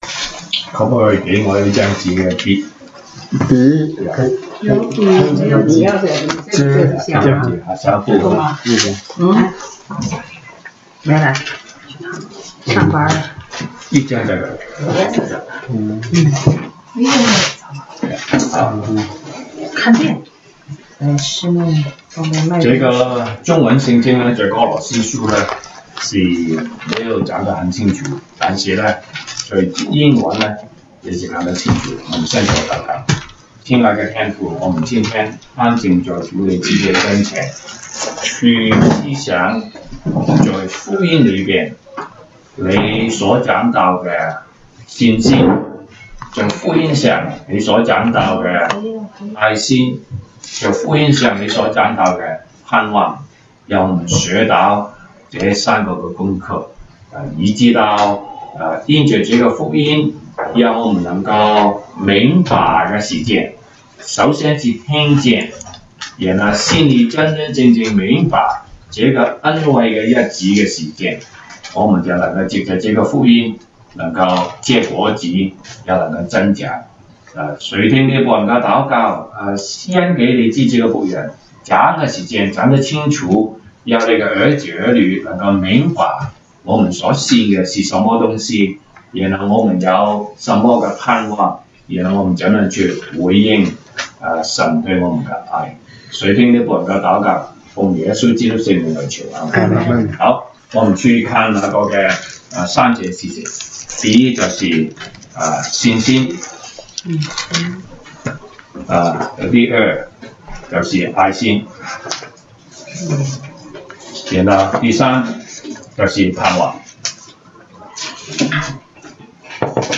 Service Type: 週一國語研經 Monday Bible Study